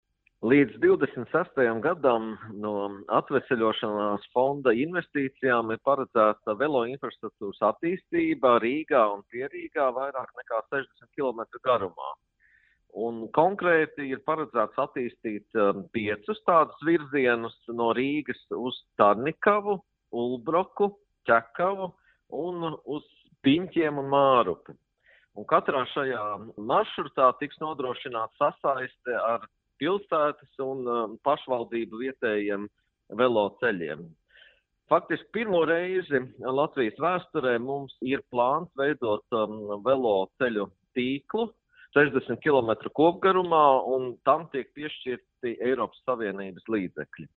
Iekļaujot veloceļu infrastruktūru vienotā satiksmes sistēmā, iespējams ievērojami paaugstināt iedzīvotāju pārvietošanās iespējas un uzlabot vides un dzīves kvalitāti reģiona iedzīvotājiem. Tādēļ Atveseļošanas fonda ietvaros plānots ievērojami paplašināt veloceļu izbūvi. Par to vairāk stāsta Satiksmes ministrs Tālis Linkaits: